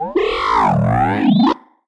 sad1.ogg